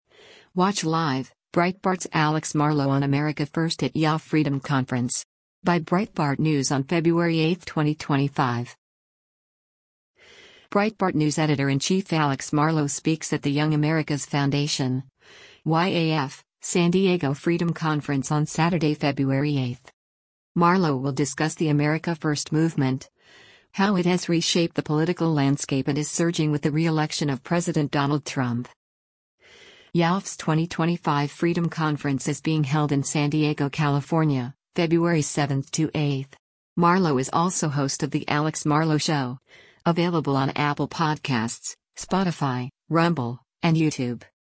speaks at the Young America’s Foundation (YAF) San Diego Freedom Conference on Saturday, February 8.
YAF’s 2025 Freedom Conference is being held in San Diego, California, February 7-8.